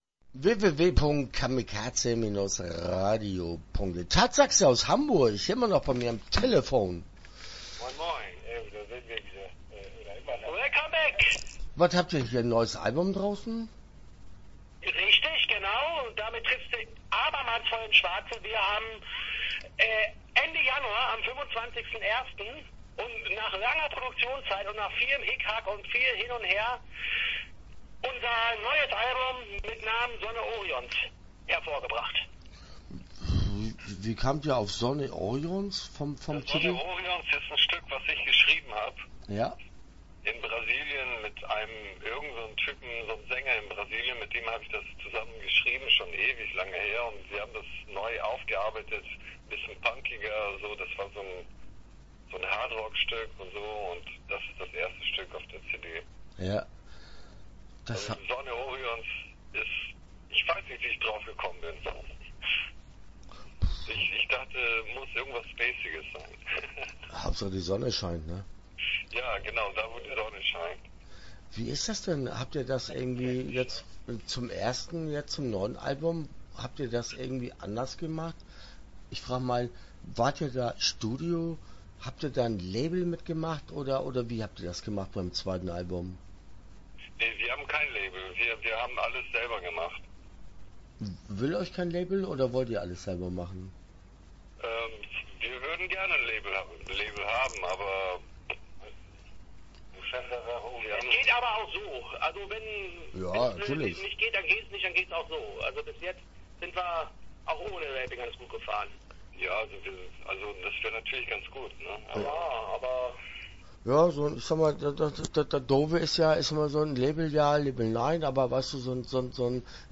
Tatsaxe - Interview Teil 1 (10:47)